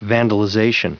Prononciation du mot : vandalization
vandalization.wav